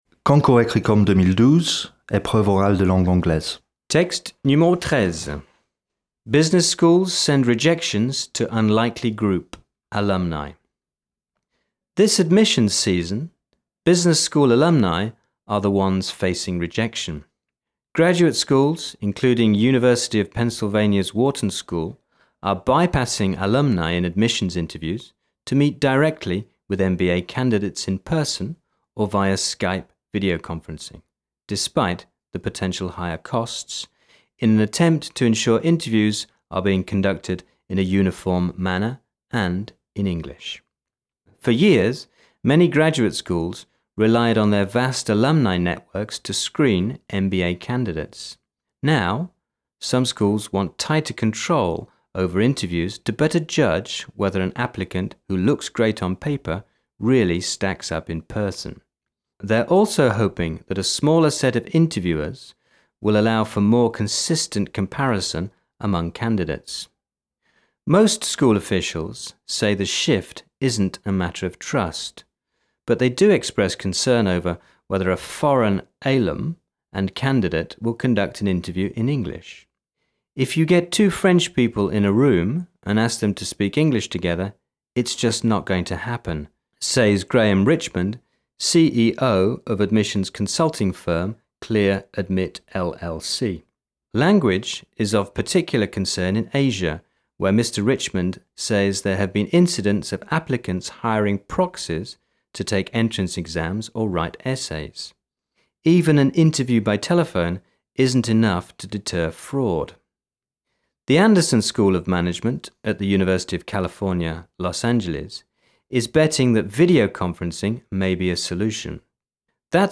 Ce sont des extraits d'articles de presse, enregistrés par des voix anglo-saxonnes, et convertis ici au format mp3.